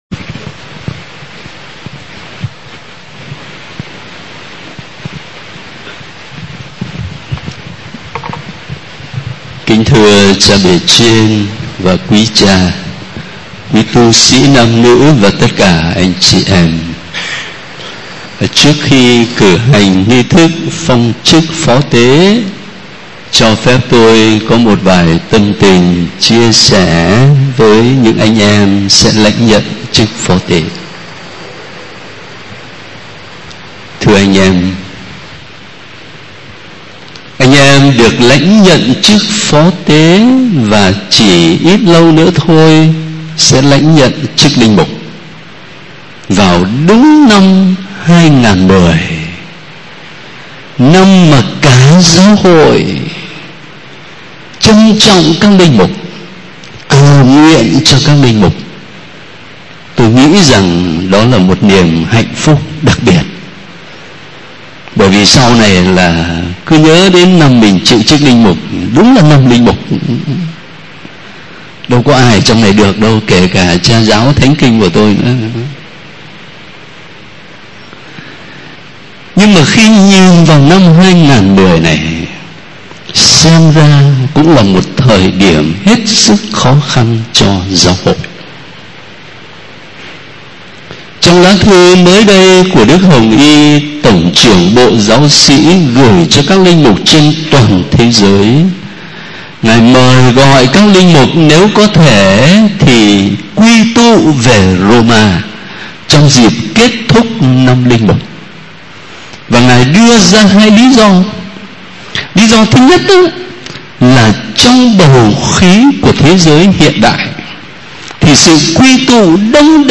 Bai giang trong Thanh Le phong chuc Pho Te do Duc Cha Kham(2).mp3